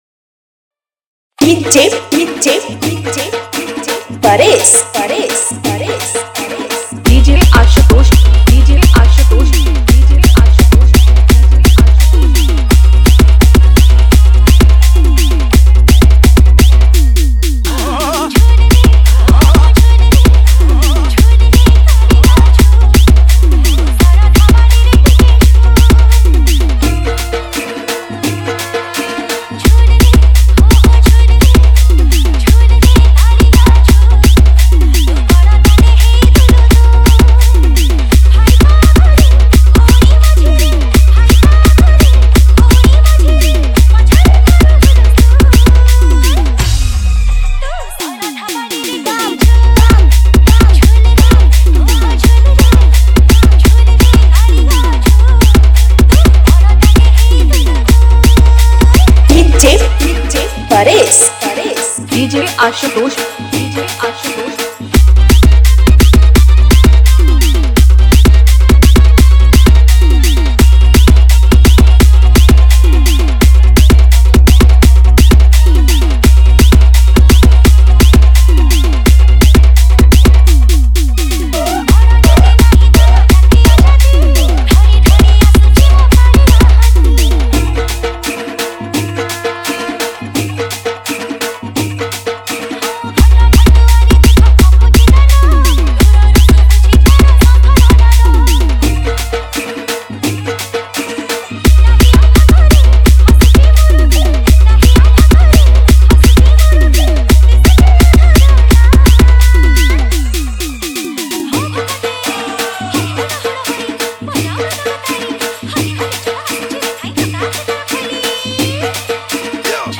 Bhajan Dj Song Collection 2022 Songs Download